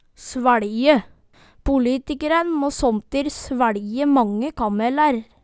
svæLje - Numedalsmål (en-US)